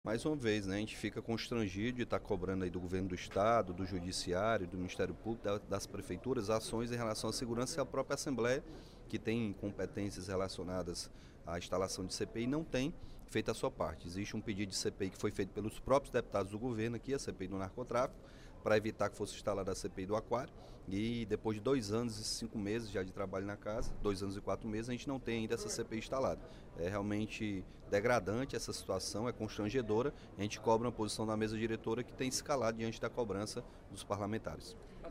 O deputado Capitão Wagner (PR) cobrou, durante o primeiro expediente da sessão plenária desta quinta-feira (04/05), a instalação da Comissão Parlamentar de Inquérito, na Assembleia Legislativa, para investigar o narcotráfico no Ceará.